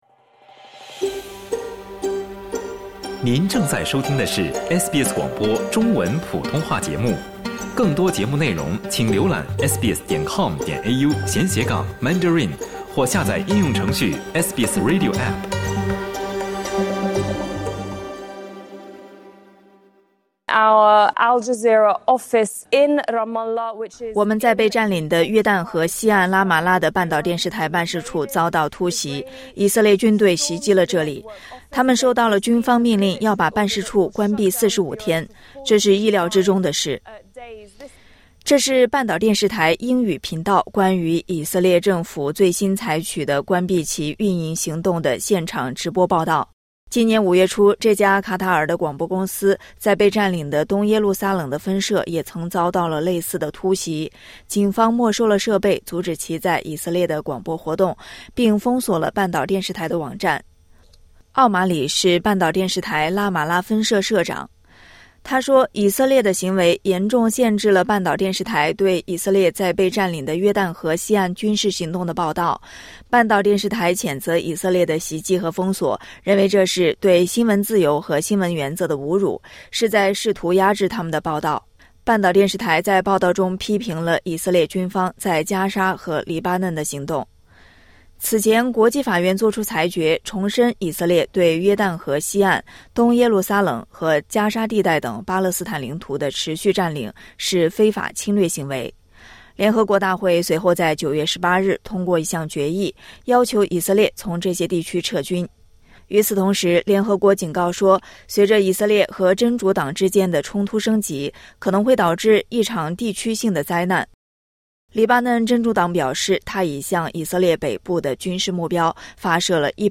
这是半岛电视台英语频道关于以色列政府最新采取的关闭其运营行动的现场直播报道。